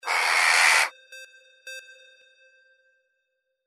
Crow Jackdaw 09A
Crow sound effect 'Caw'
Stereo sound effect - Wav.16 bit/44.1 KHz and Mp3 128 Kbps